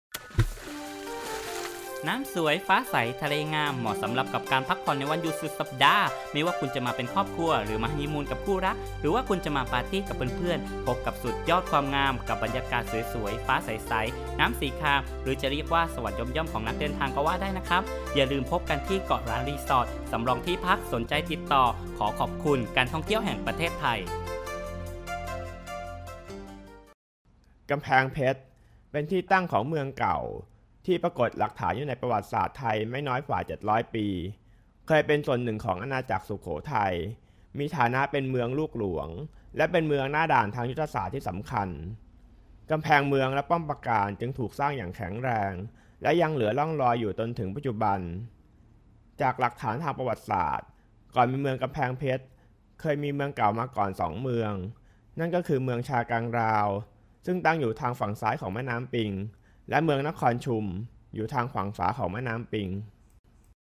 Thai male for radio narration documentary
Sprechprobe: eLearning (Muttersprache):